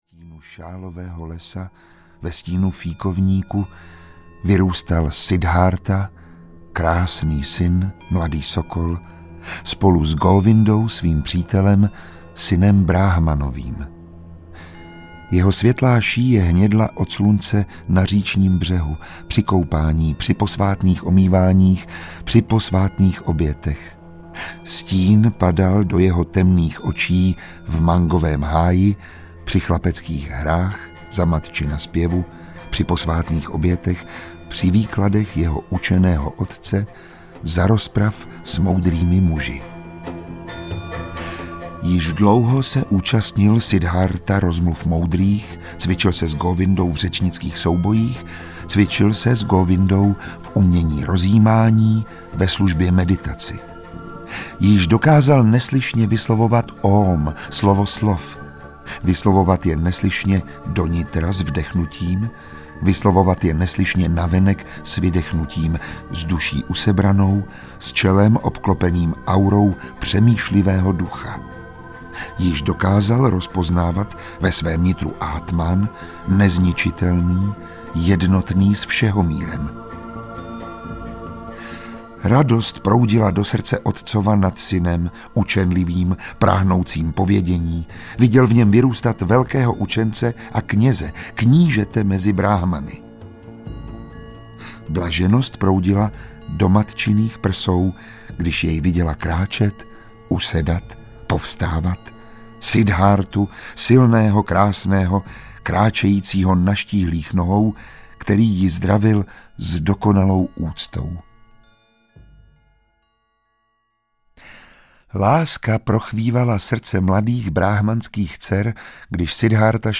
Siddhártha audiokniha
Ukázka z knihy
• InterpretMiroslav Táborský